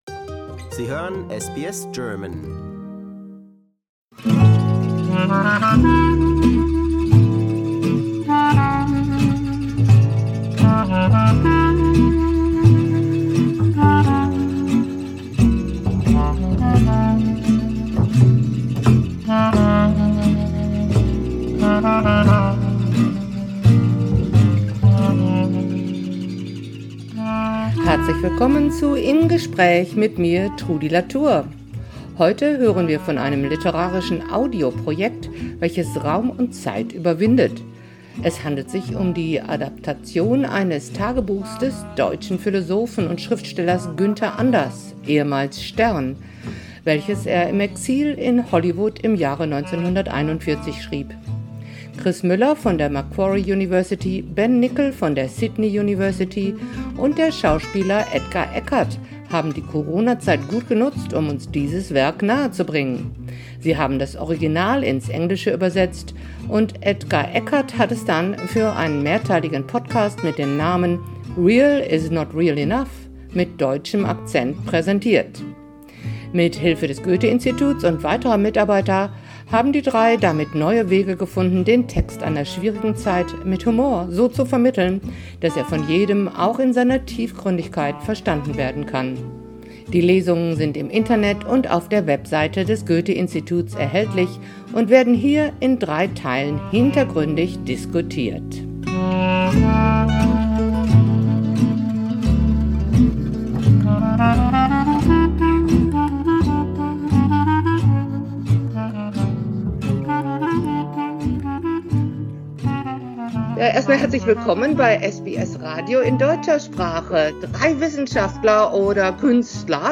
Im Gespräch über "Real is not Real Enough" Source